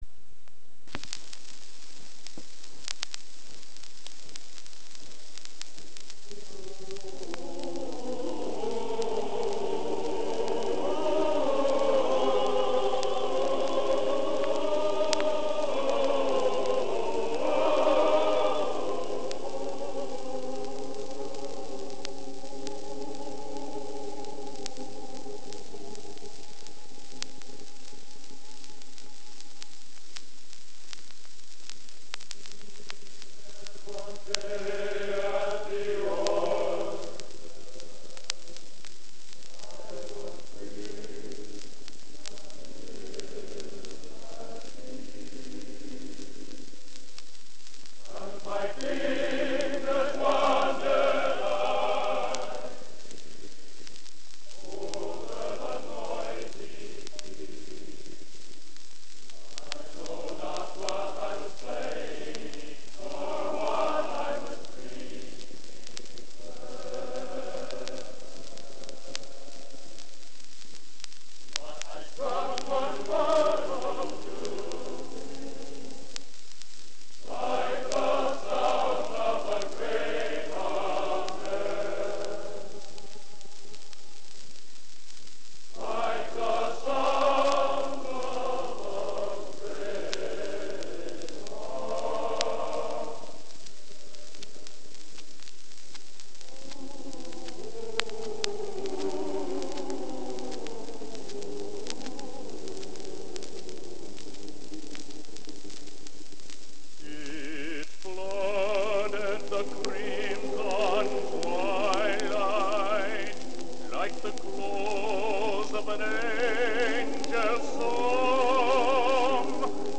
Genre: | Type: Featuring Hall of Famer |Studio Recording